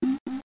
radar.mp3